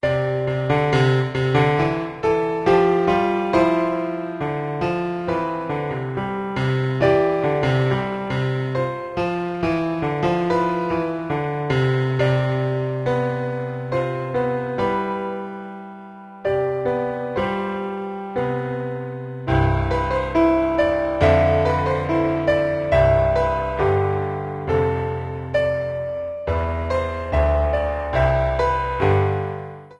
trimmed & added fadeout You cannot overwrite this file.